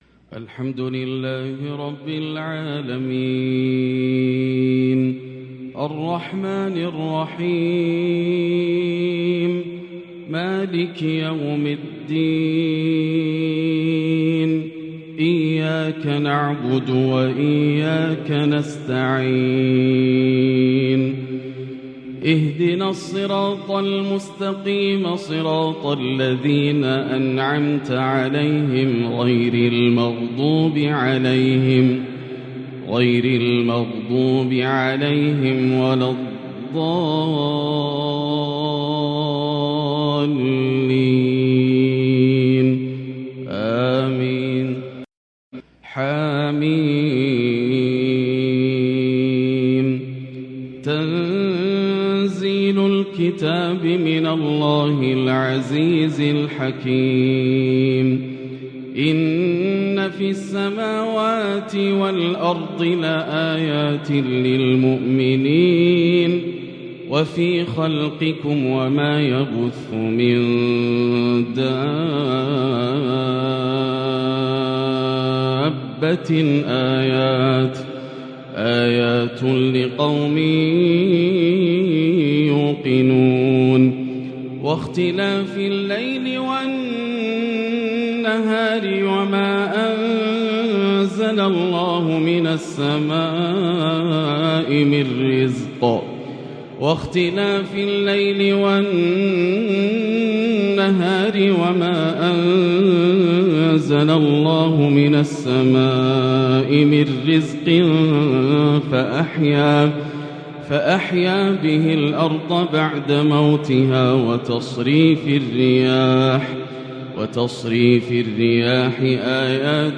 تلاوة في غاية الجمال والبَهاء من سورة الجاثية للشيخ د. ياسر الدوسري | عشاء الأربعاء 2-3-1444هـ > تلاوات عام 1444هـ > مزامير الفرقان > المزيد - تلاوات الحرمين